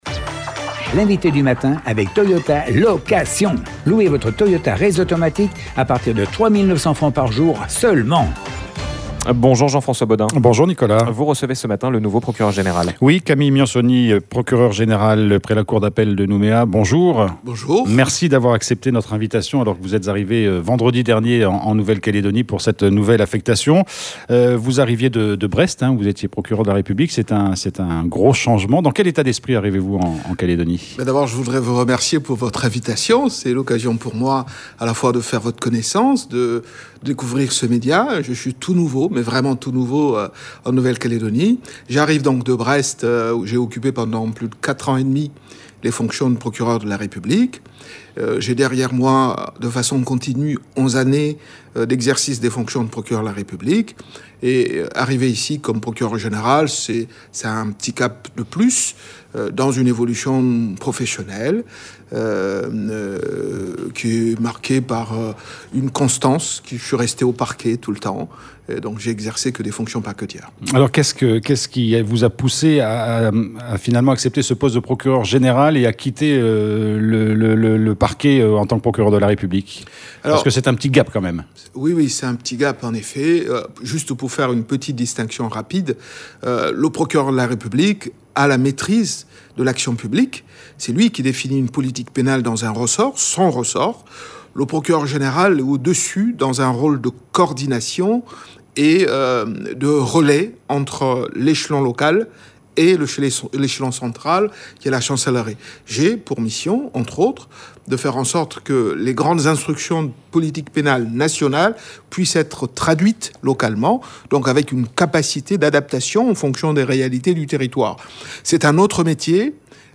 L'INVITÉ DU MATIN : CAMILLE MIANSONI
Quel est son parcours ? Comment envisage-t-il de mener sa fonction en Nouvelle-Calédonie ? Camille Miansoni, nouveau Procureur Général, était notre invité à 7h30.